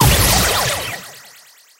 大招撞击
BS_lily_ulti_hit_01.mp3